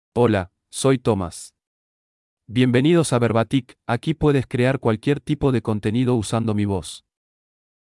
MaleSpanish (Argentina)
Voice sample
Male
Spanish (Argentina)